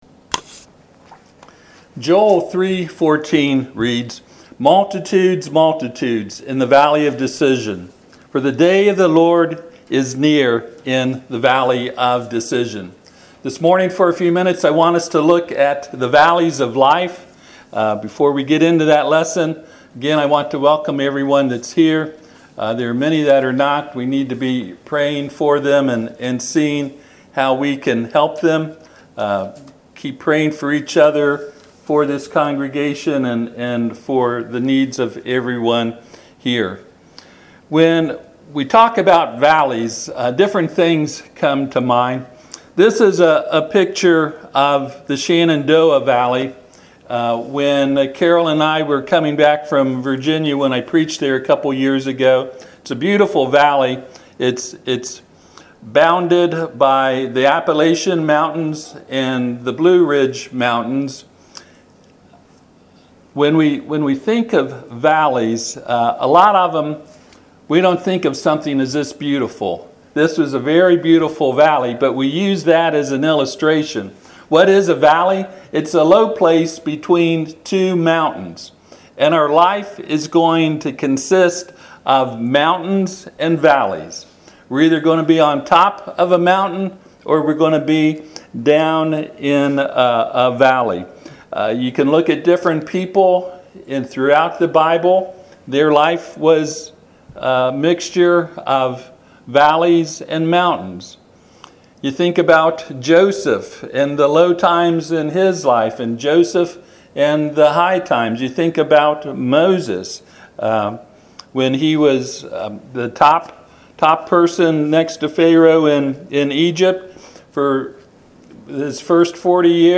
Joel 3:14 Service Type: Sunday AM Topics: Anxiety , Depression , despair , Hope « Promise Verses To Stand On.